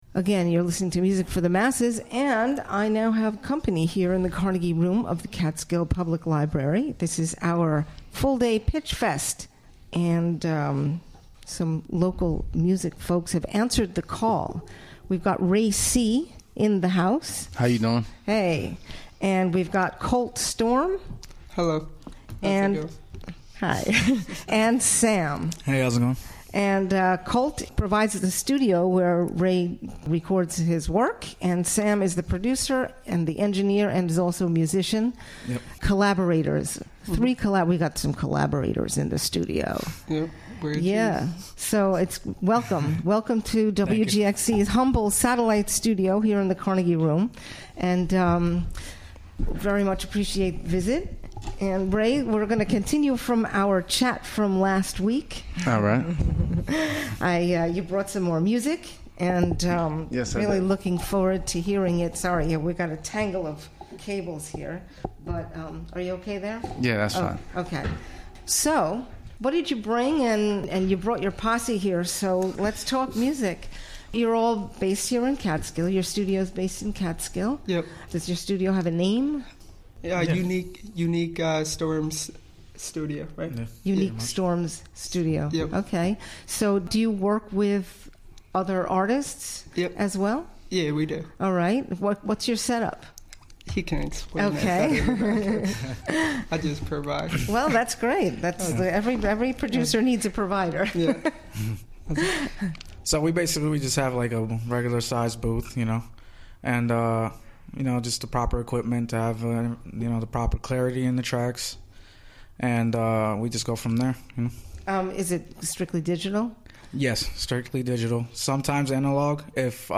broadcasts
Interviewed